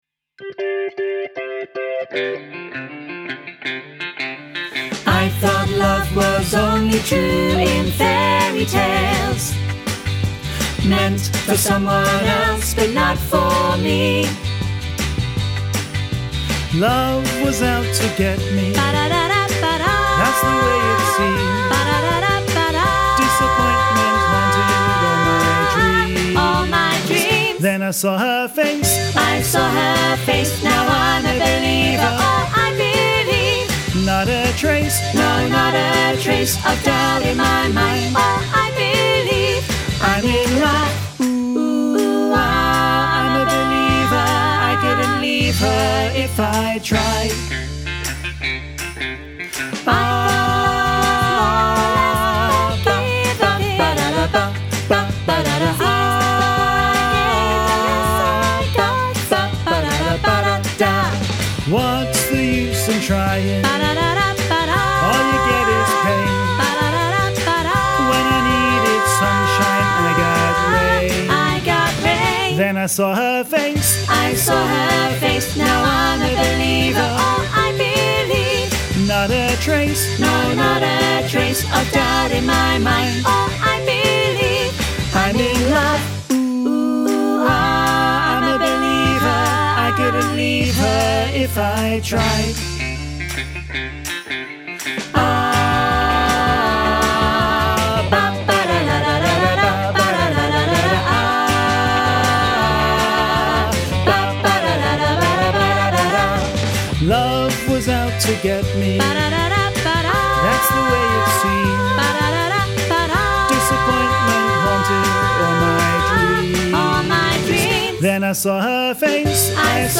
Training Tracks for I'm a Believer
im-a-believer-full-mix.mp3